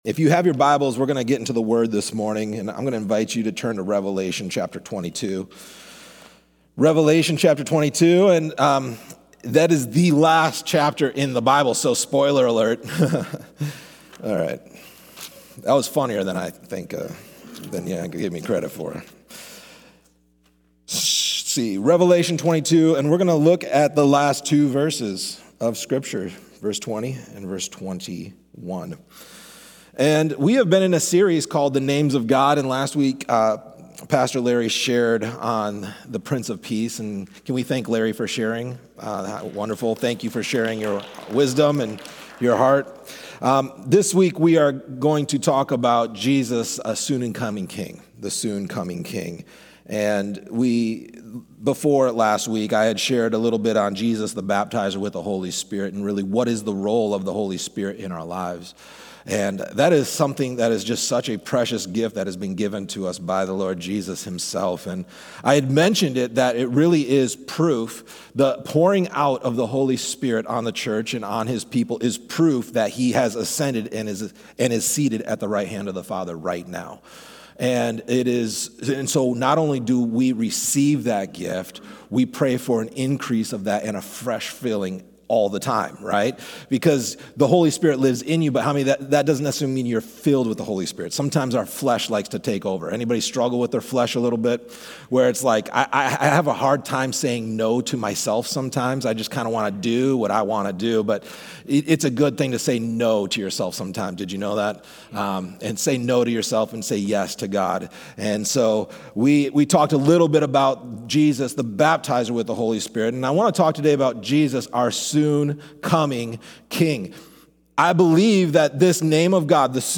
A message from the series "Names Of God."